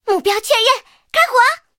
SU-26开火语音2.OGG